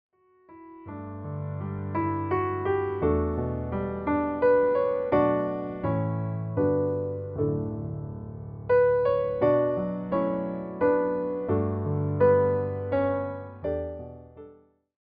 each one carrying a warm, traditional Christmas feeling.